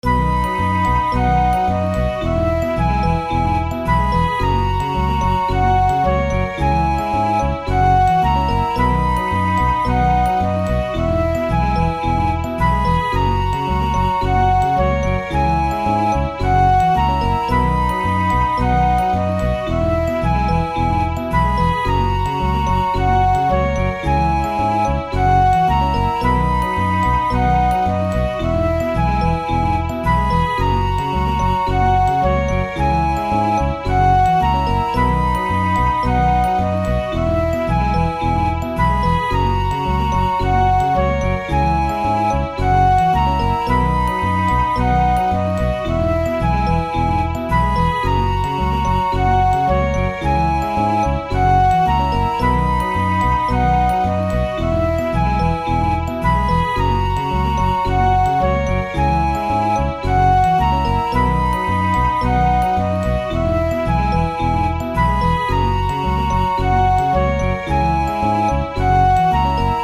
明るい・爽やか
日常の楽しいシーンをイメージした超シンプルな曲。
Aは曲の終わり有り。Bはループ対応版です。